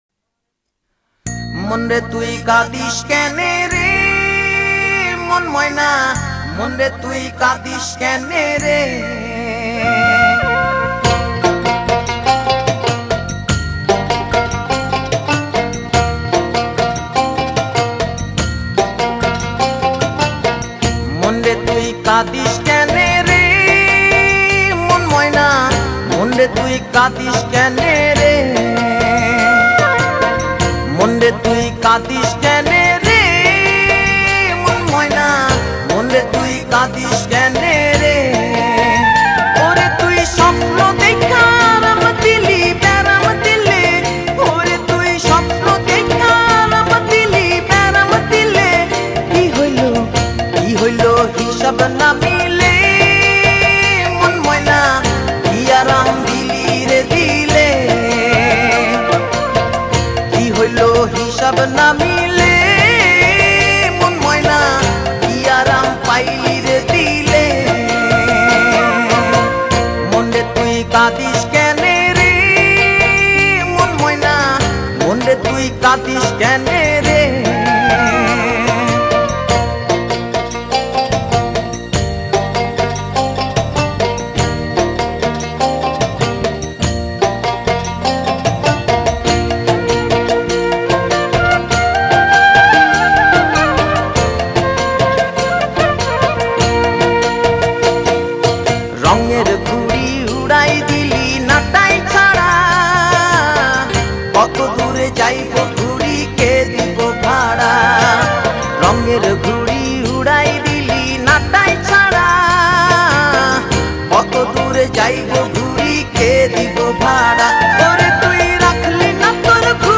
Genre Bangla Pop